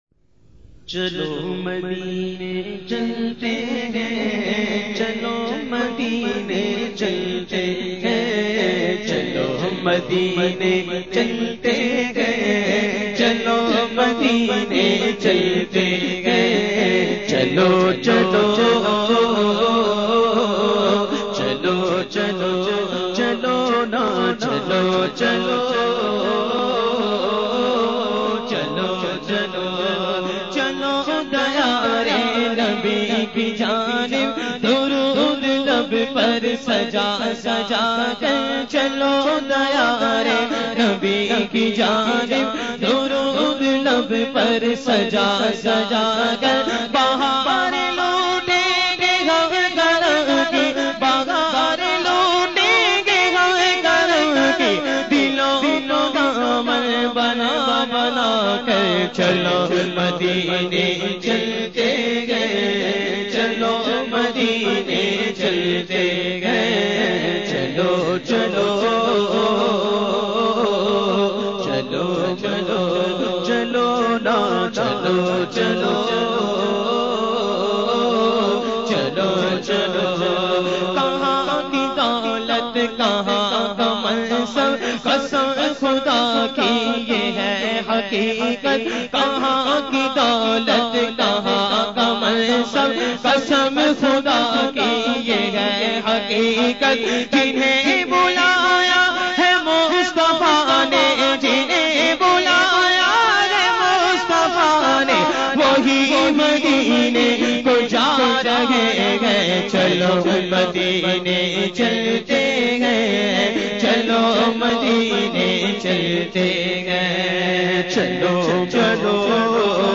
Islamic Qawwalies And Naats